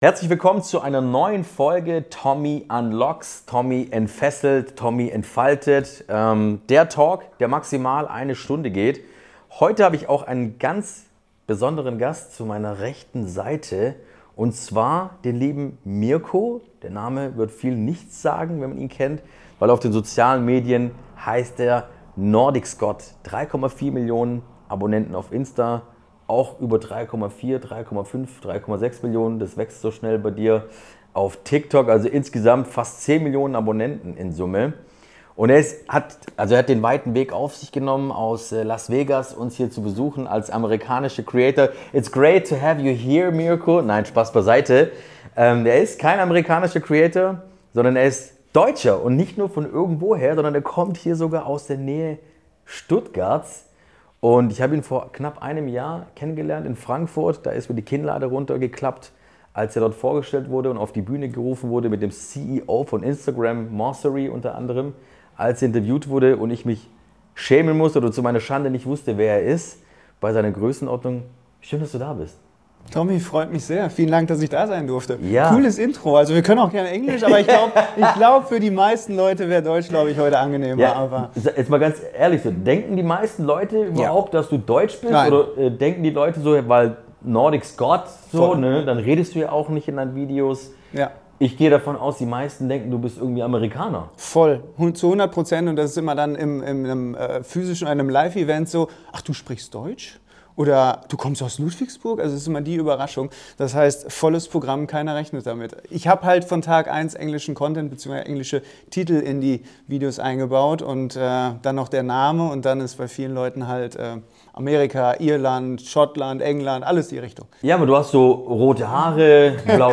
Ein ehrliches Gespräch über Verantwortung, Chancen und was unternehmerischer Erfolg heute wirklich bedeutet.